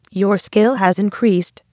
skillup.wav